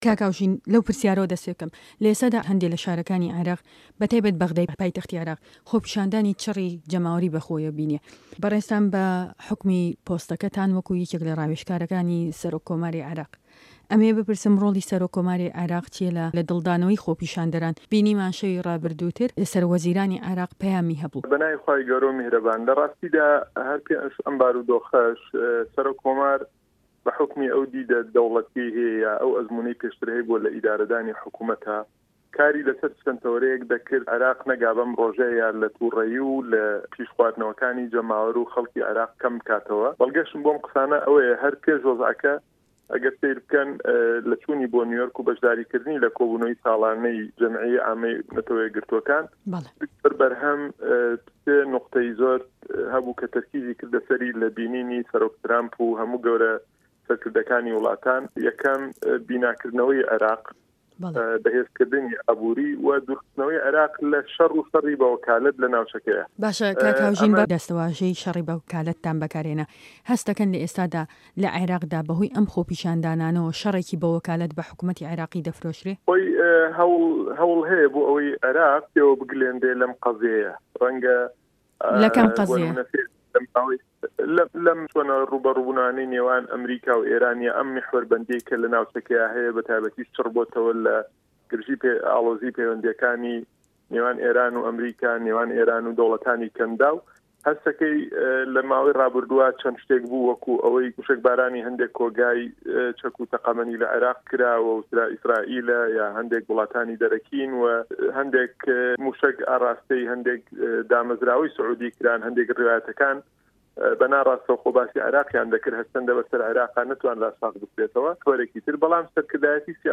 ده‌قی وتوێژه‌كه‌